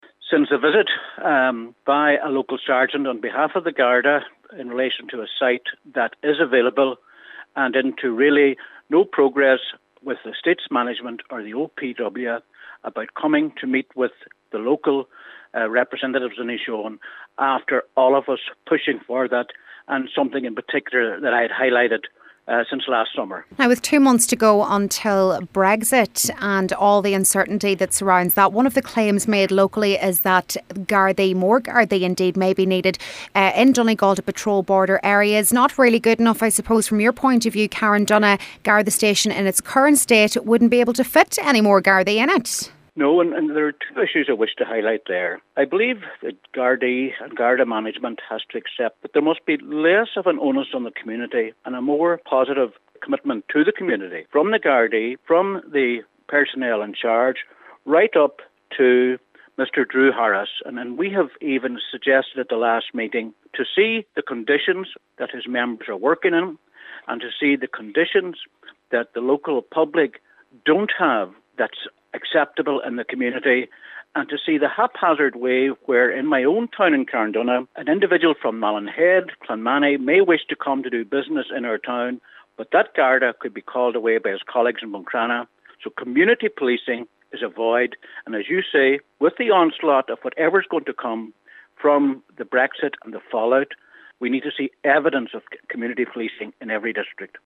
Cllr Albert Doherty says with such uncertainty surrounding Brexit and the possibility of the need for more policing in border areas, now is the time for the issue to be addressed: